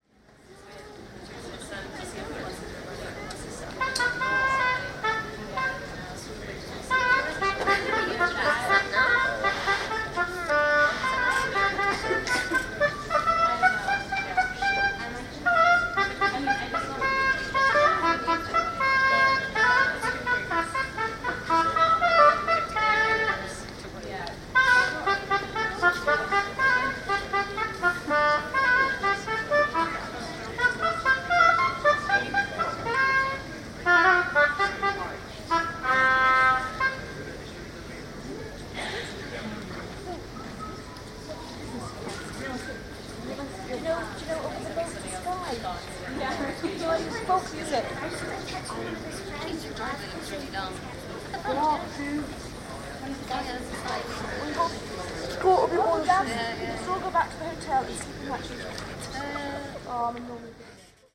Oboe Practice at the Departure Gate
Music Practice